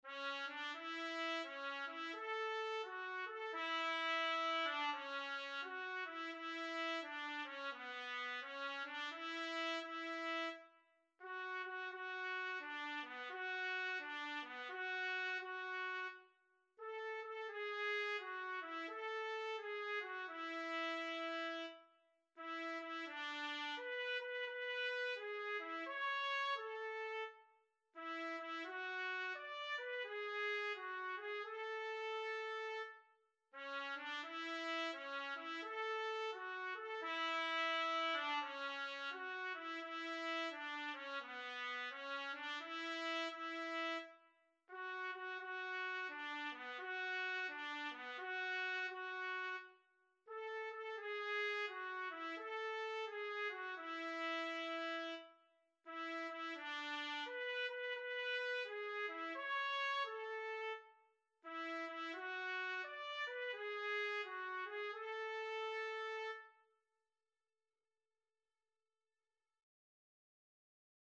Language: Portuguese Instruments: Organ